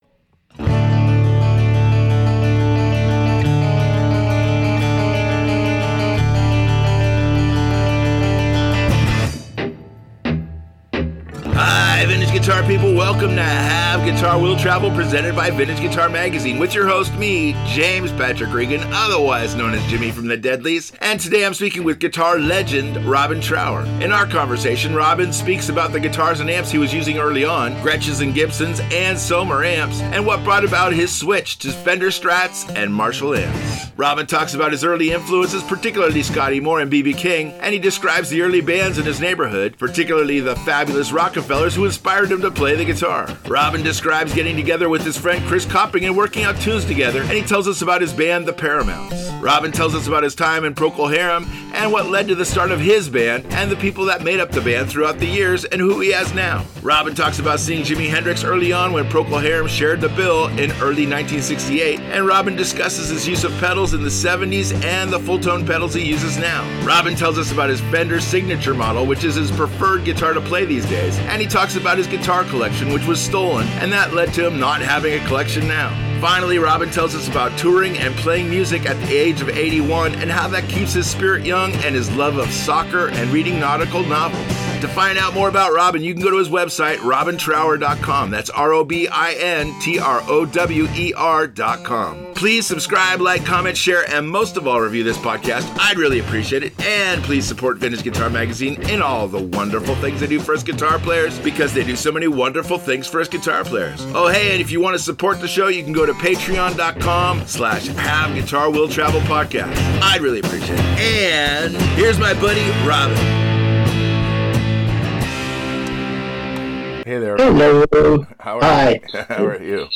Today I’m speaking with Guitar Legend Robin Trower. In our conversation Robin speaks about the guitars and amps he was using early on, Gretsch and Gibson’s and Selmer Amps and what brought about his switch to Fender Strats and Marshall amps.